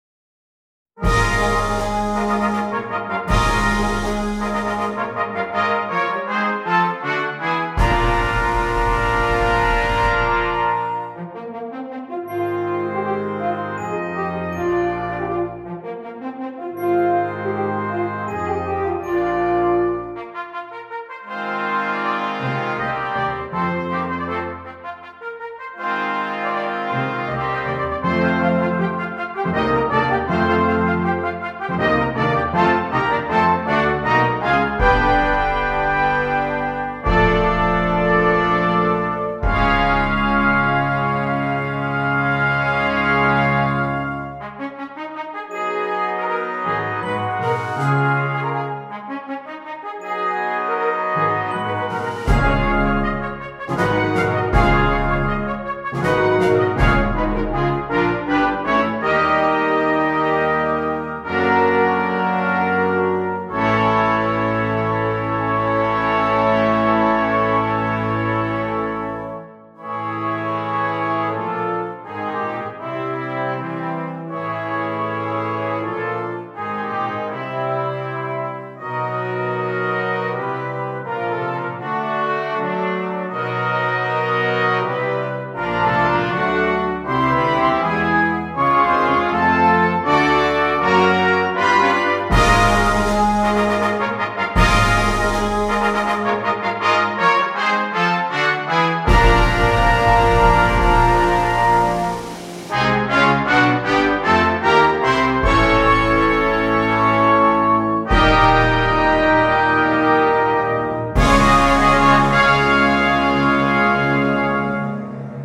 Brass Band